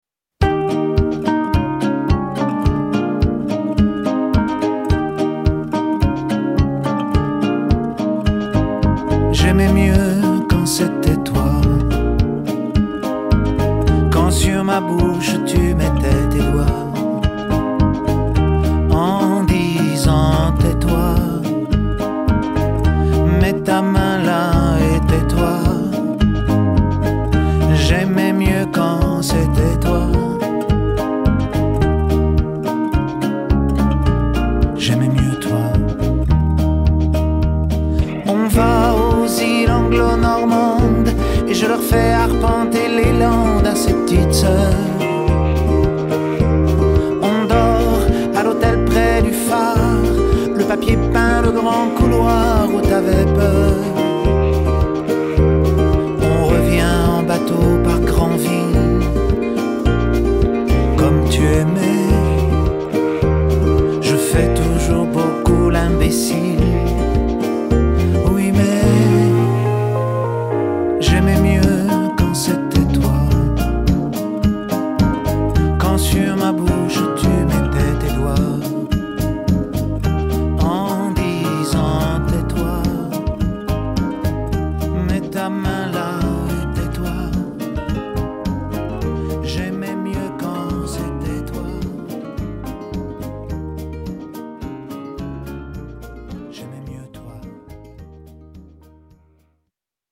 tonalité MIb majeur